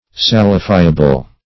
Search Result for " salifiable" : The Collaborative International Dictionary of English v.0.48: Salifiable \Sal"i*fi`a*ble\, a. [Cf. F. salifiable.